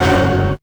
55bf-orc13-f#3.wav